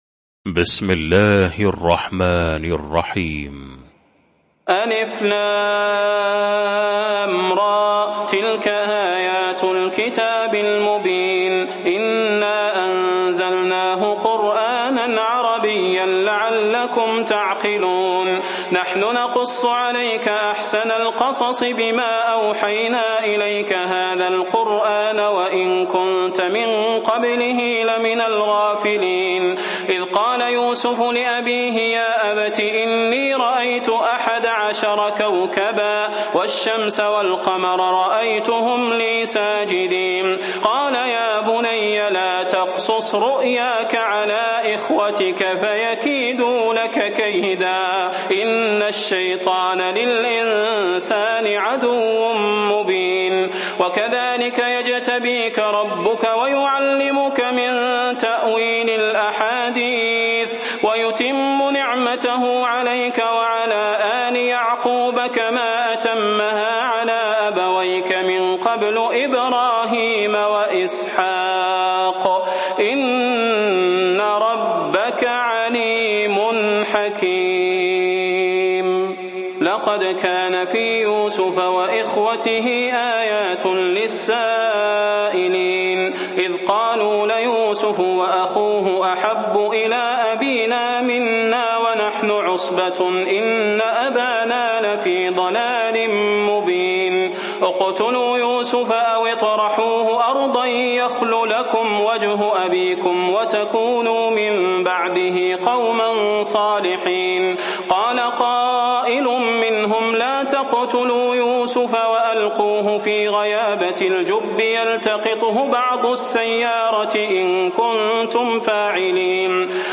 المكان: المسجد النبوي الشيخ: فضيلة الشيخ د. صلاح بن محمد البدير فضيلة الشيخ د. صلاح بن محمد البدير يوسف